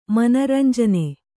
♪ mana ranjane